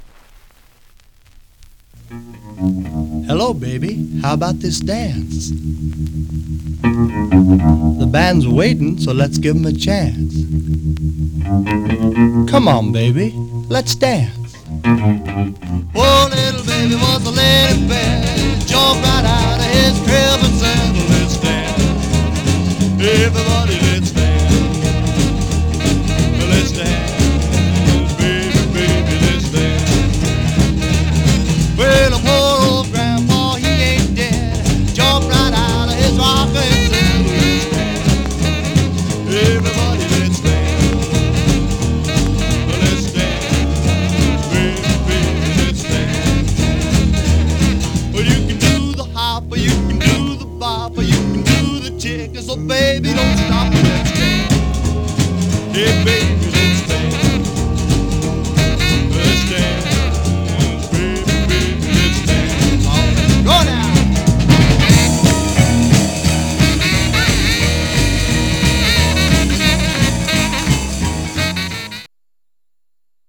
Rockabilly